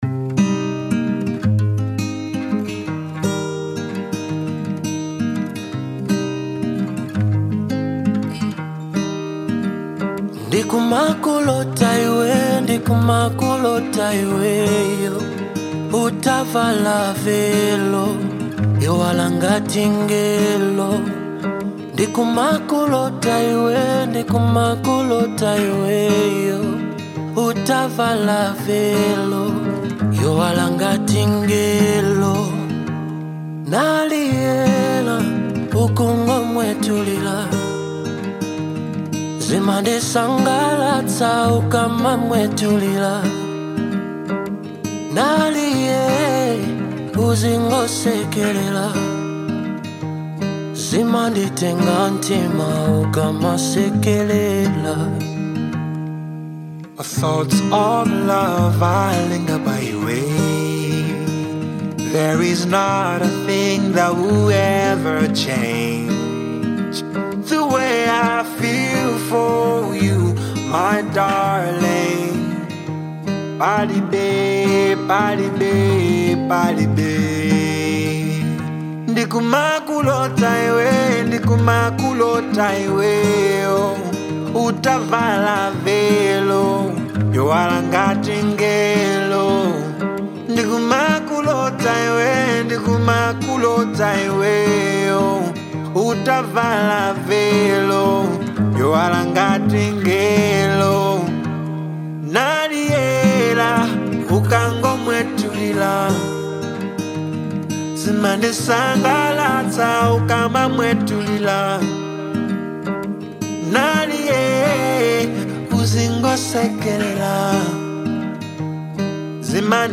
Genre : RnB
featuring the smooth vocals